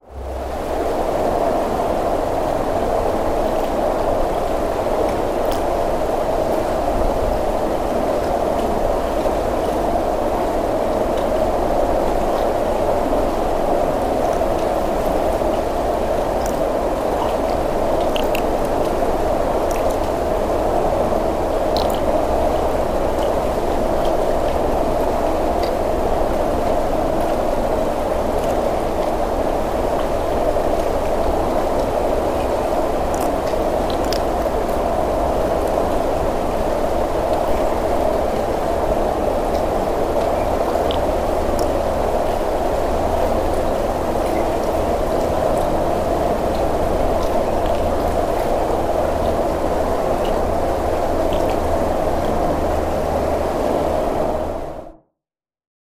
Auronzo di Cadore, Italy, September 2013.